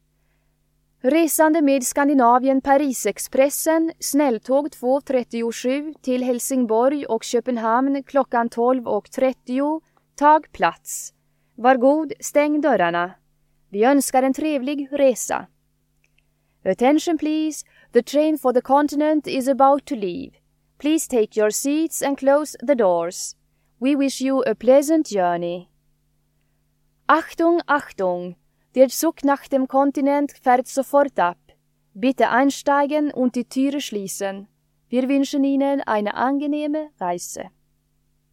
Högtalarutrop Stockholms central